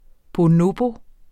Udtale [ boˈnobo ]